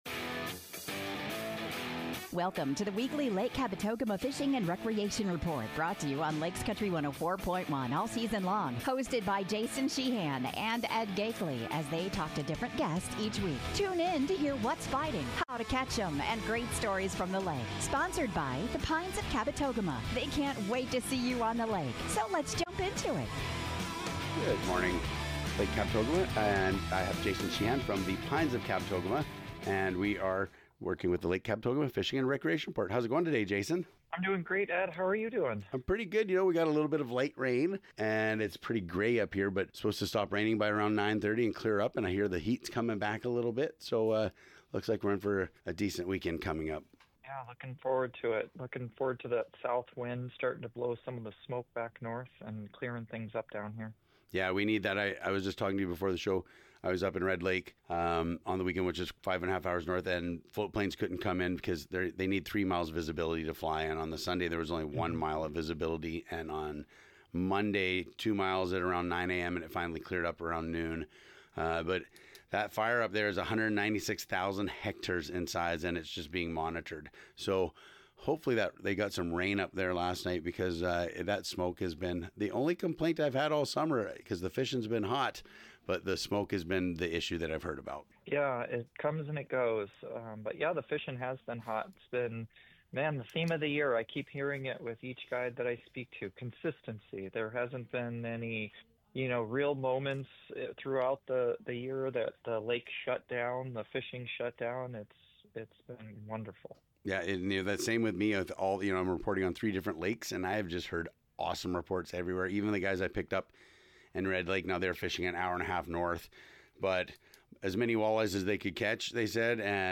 sits down at Arrowhead Lodge & Outfitters